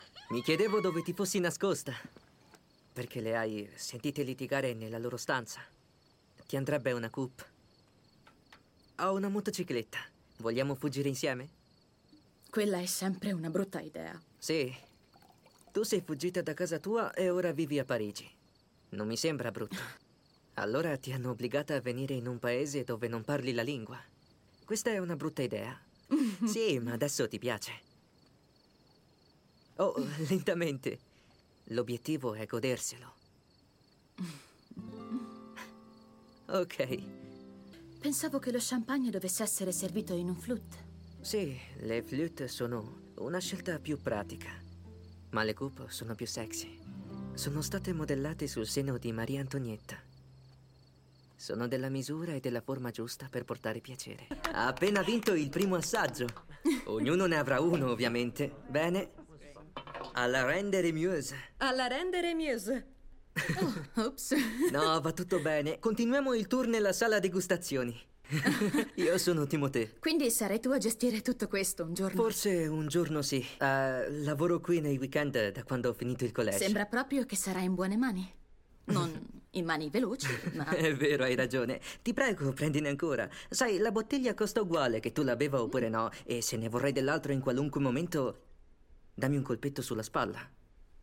nel telefilm "Emily in Paris"